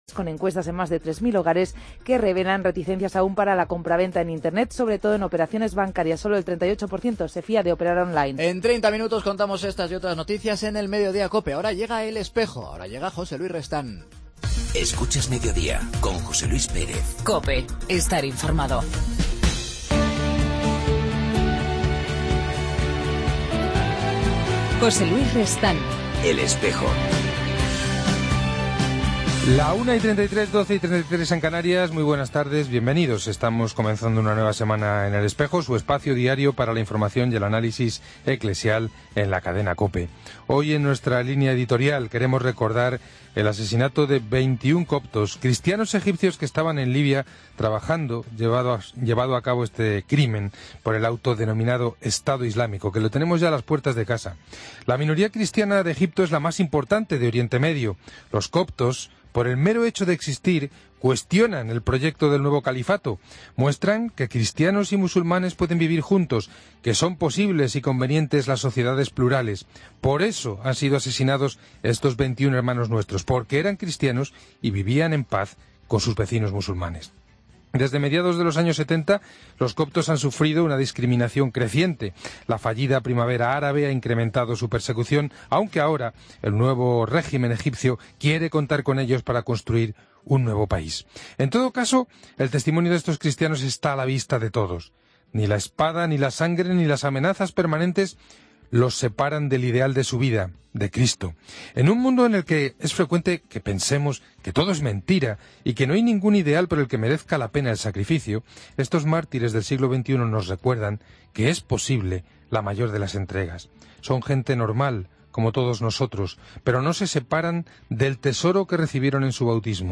AUDIO: Hoy en El Espejo ha estado con nosotros el Subsecretario del Consejo Pontificio para los laicos, el sacerdote español Miguel Delgado...